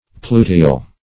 pluteal - definition of pluteal - synonyms, pronunciation, spelling from Free Dictionary Search Result for " pluteal" : The Collaborative International Dictionary of English v.0.48: Pluteal \Plu"te*al\, a. (Zool.) Of or pertaining to a pluteus.